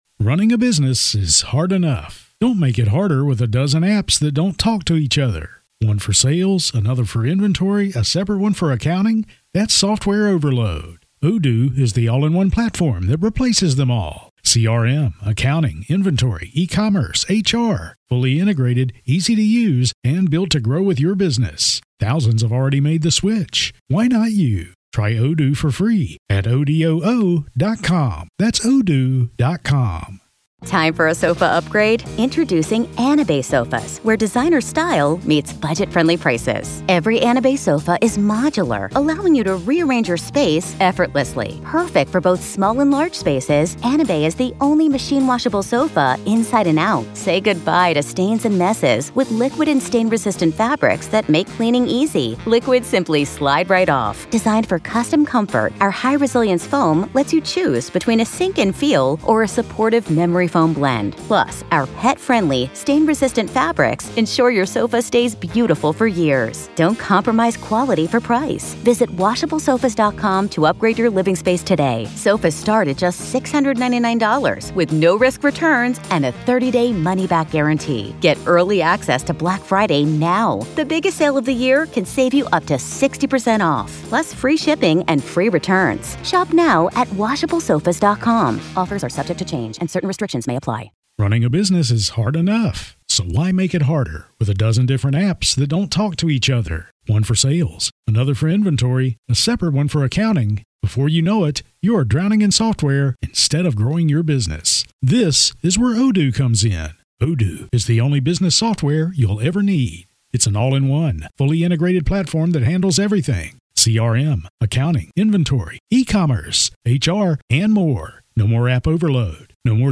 In this gripping conversation